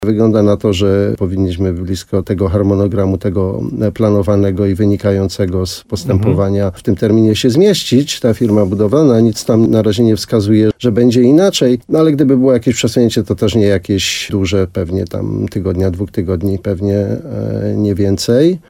– Po ostatniej naradzie na budowie wiemy, że planowany termin zakończenia prac nie jest zagrożony – powiedział wójt gminy Szczawa, Janusz Opyd.